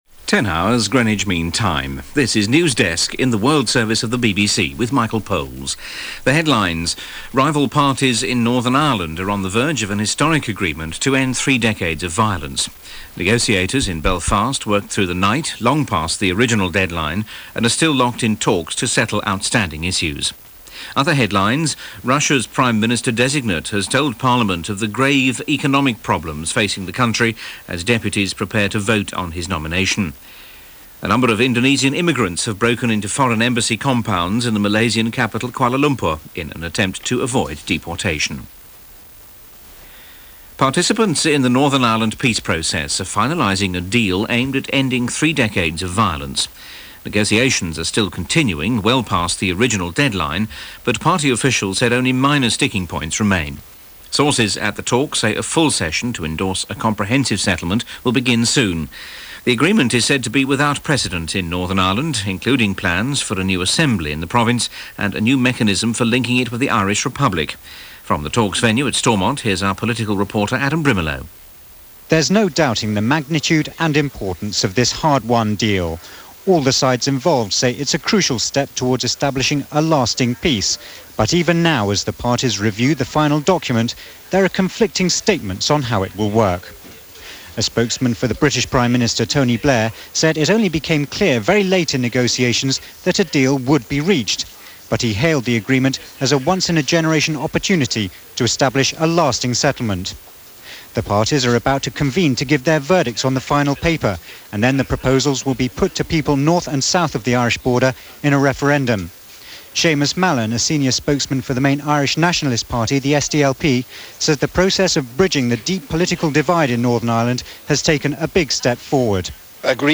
– BBC World Service – Newsdesk – April 10, 1998 – BBC World Service